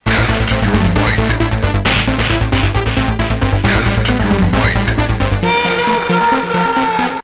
Ten sam oryginalny fragment utworu został zpróbkowany z komputera PC samplerem produkcji BIW.
Następnie odtworzone fragmenty z pamięci RAM commodore zostały zsamplowane przez kartę dźwiękową komputera PC i zapisane w formacie 22kHz 16 bitów mono.
8 bit covox $a5]
mortal-8bit-covox-a5.wav